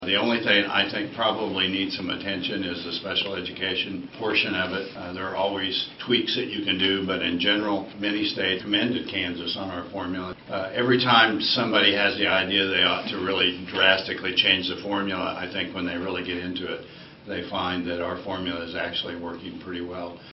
MANHATTAN — The Manhattan-Ogden USD 383 Board of Education held a special work session Wednesday with Kansas lawmakers regarding some of the issues they hope to see brought up in the upcoming legislative session.